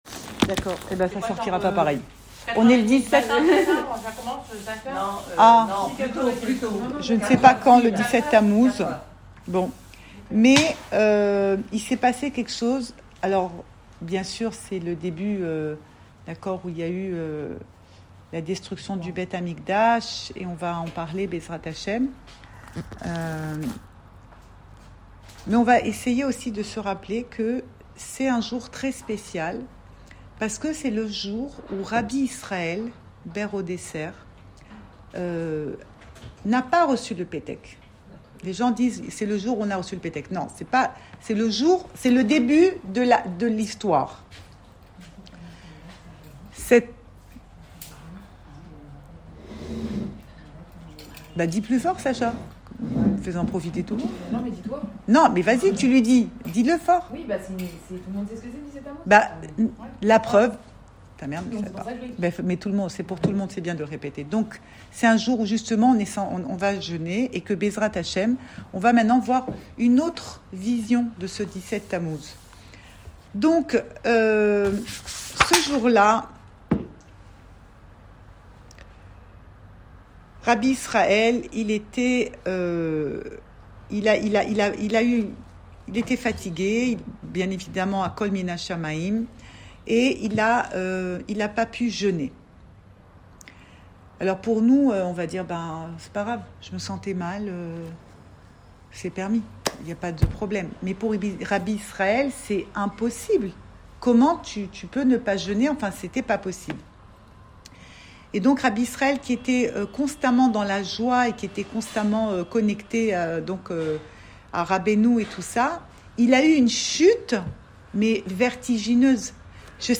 le 17 Tamouz Cours audio Le coin des femmes Pensée Breslev
Enregistré à Tel Aviv